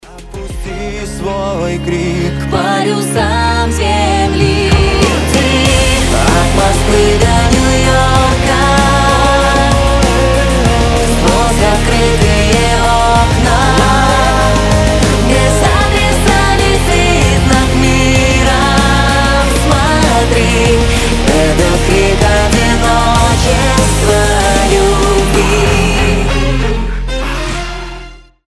• Качество: 320, Stereo
громкие
дуэт
Pop Rock
Mashup
ремиксы